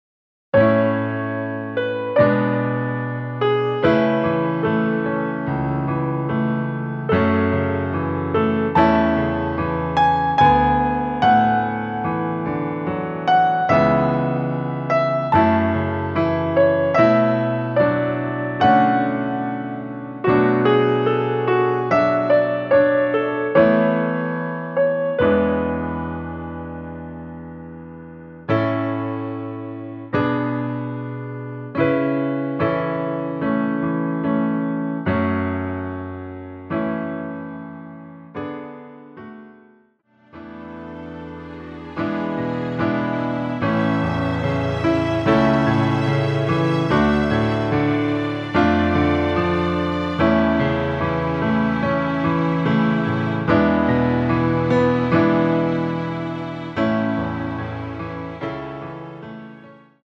피아노와 스트링만으로 편곡한 MR 입니다.
앞부분30초, 뒷부분30초씩 편집해서 올려 드리고 있습니다.